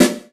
SNARE 079.wav